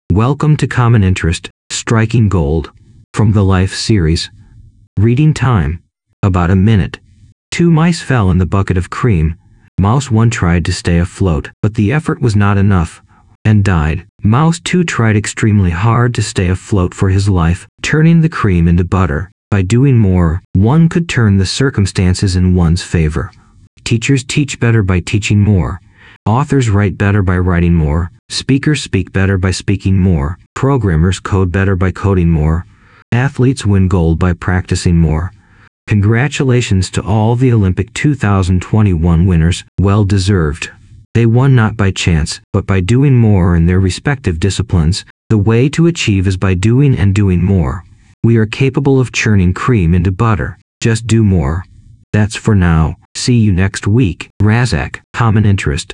For those who prefer listening vs reading, I have an audio version of the blog.